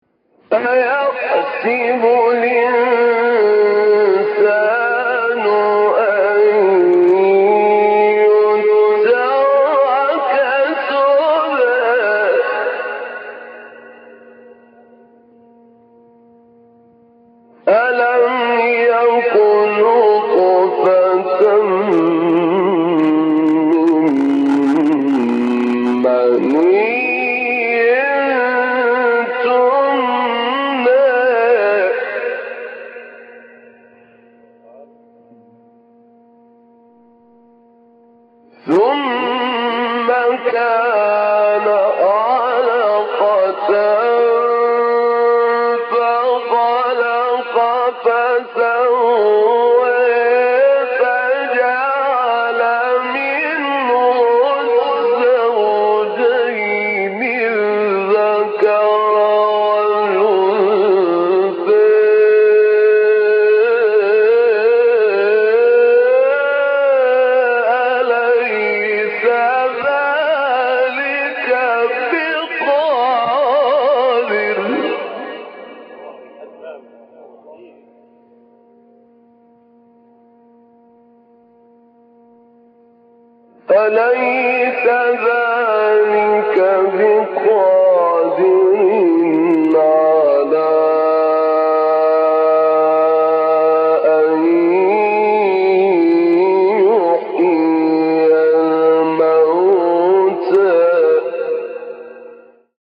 مقام نهاوند استاد طاروطی | نغمات قرآن | دانلود تلاوت قرآن